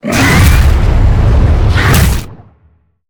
Sfx_creature_chelicerate_seatruckattack_enter_01.ogg